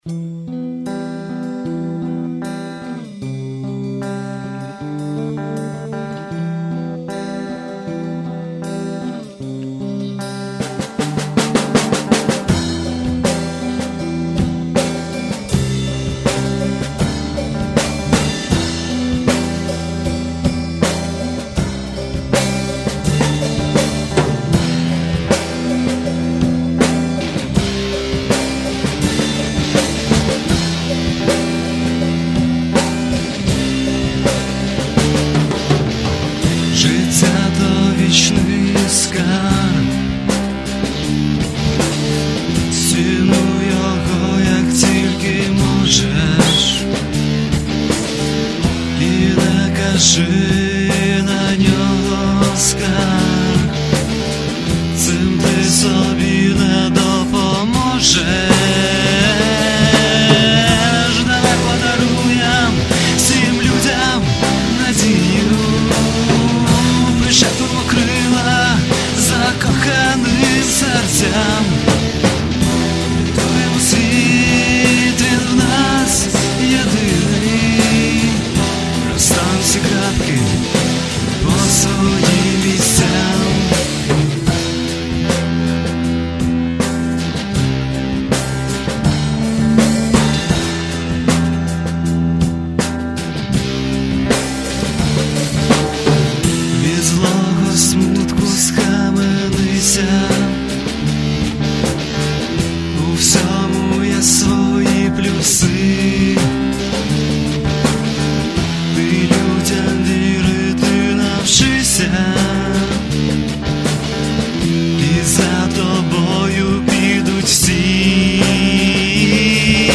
гитара, вокал
бас-гитара
ударные
бек-вокал